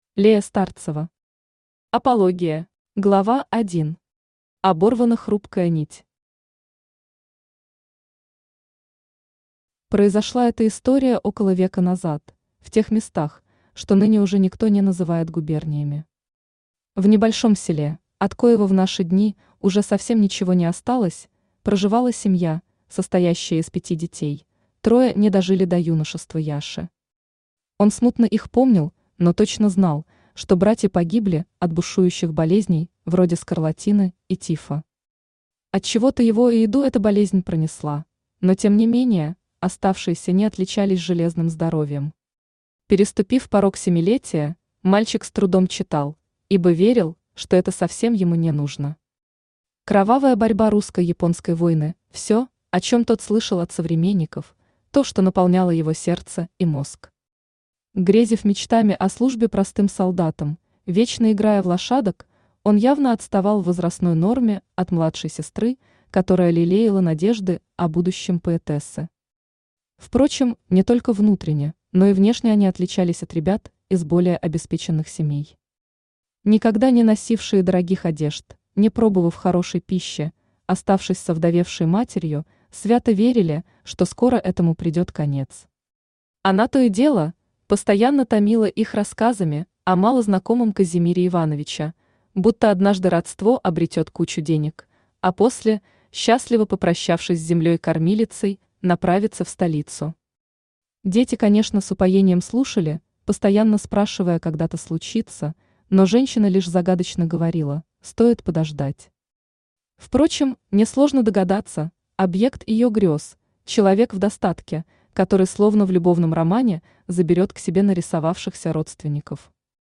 Aудиокнига Апология Автор Лея Старцева Читает аудиокнигу Авточтец ЛитРес.